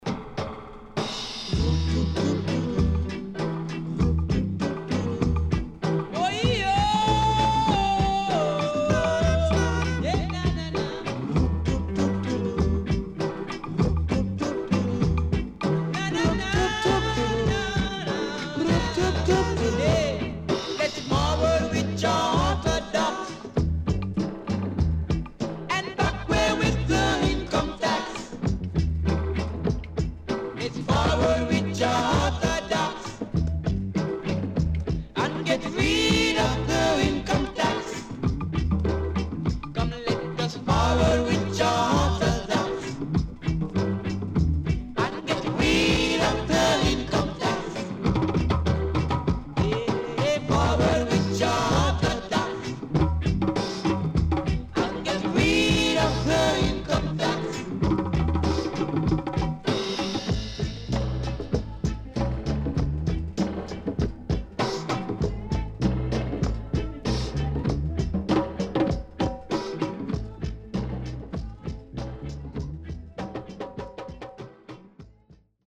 重厚でゆっくりとしたriddimにNyahbingi Drumが映えるDeep Roots.Good Condition
SIDE A:少しチリノイズ入りますが良好です。